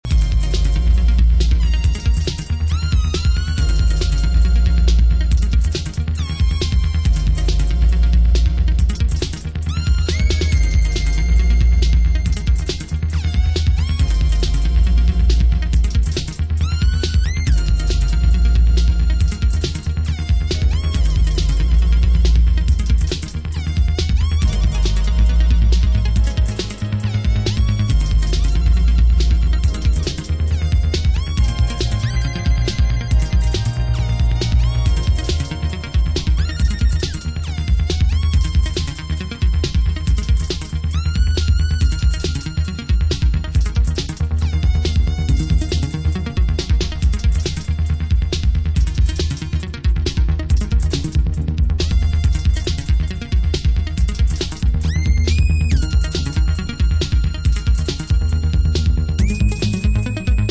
Electro
Techno